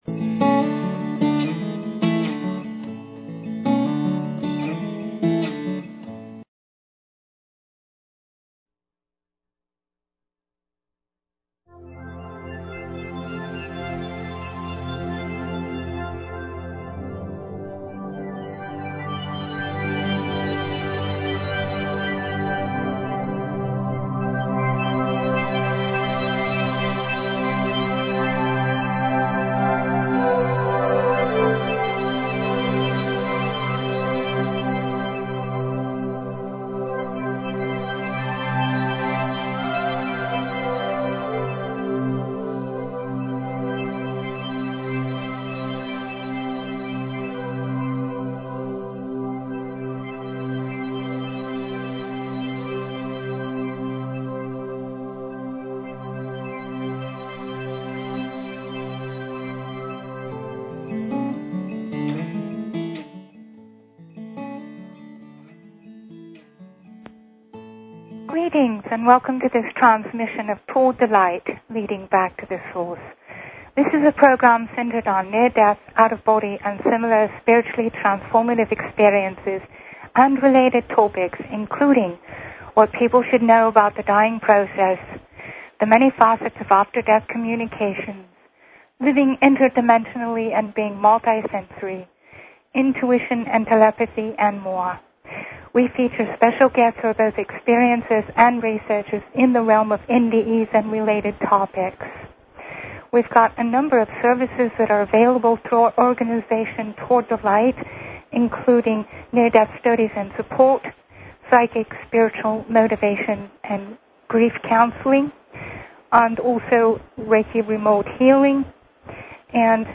Talk Show Episode, Audio Podcast, Miracles_Happen and Courtesy of BBS Radio on , show guests , about , categorized as